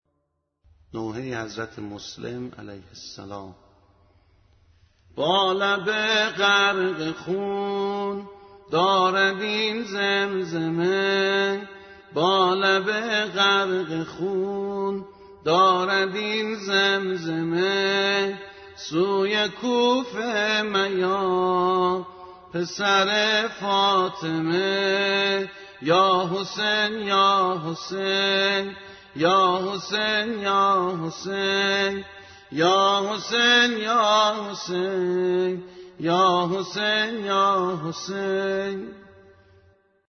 به همراه فایل صوتی سبک